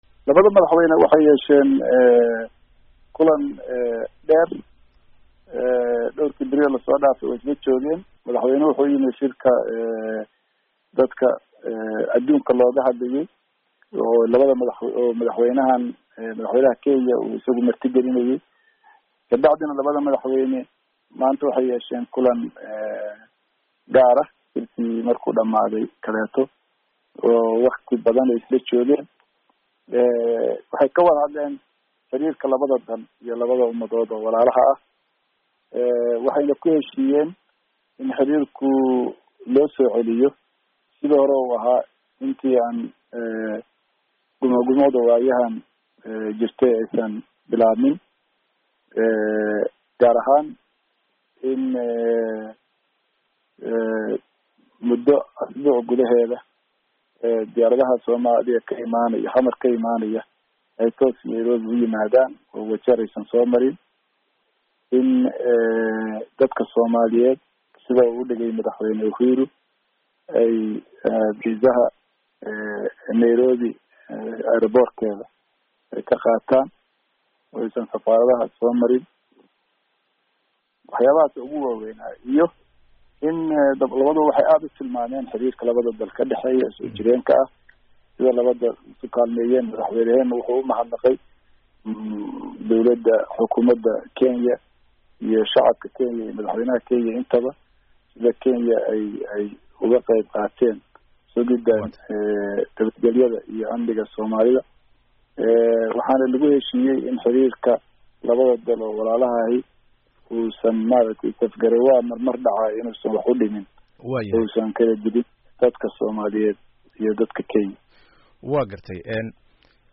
Cawad oo wareysi siiyey VOA ayaa ku adkeystay in la isku raacay in labada dal ay xiriir wanaagsan yeeshaan, oo kiiska maxkamadda ICJ uusan wax saameyn ah ku yeelan.